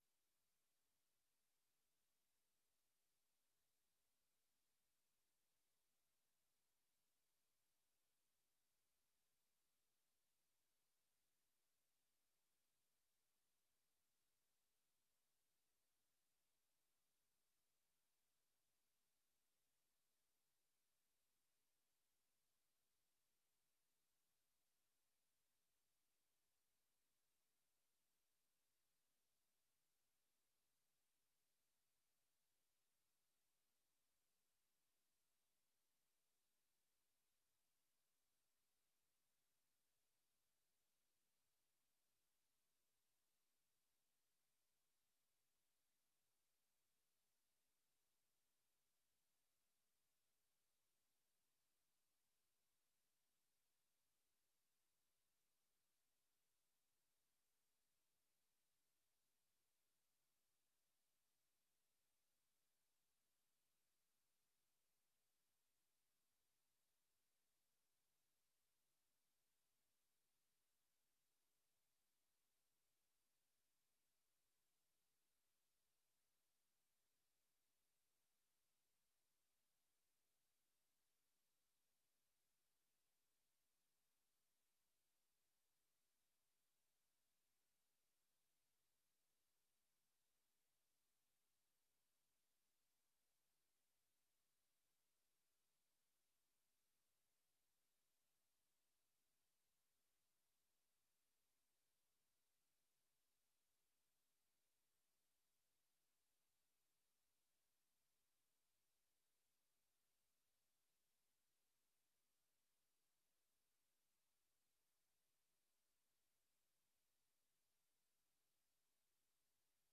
Download de volledige audio van deze vergadering
Locatie: Voorrondezaal Lingewaal